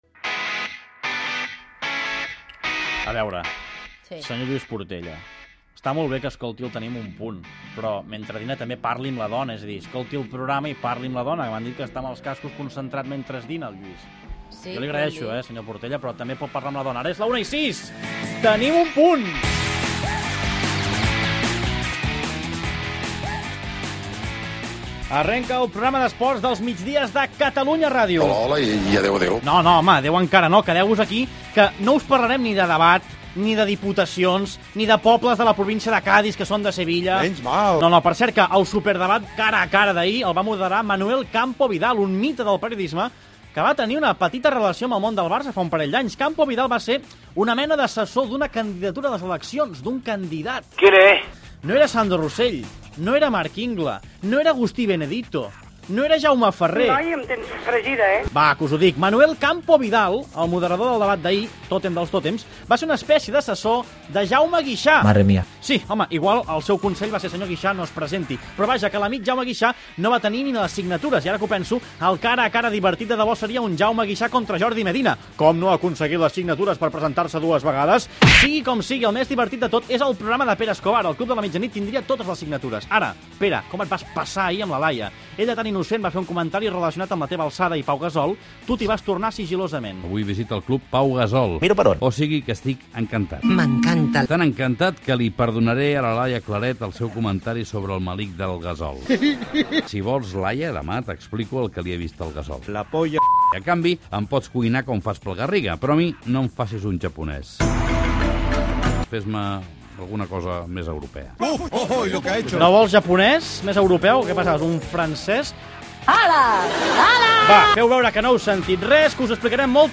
Presentació inicial, indicatiu del programa, resum d'actualitat esportiva, concurs, tema musical, connexió amb el camp de la Feixa Llarga on s'entrena l'equip masculí de futbol de L'Hospitalet Gènere radiofònic Esportiu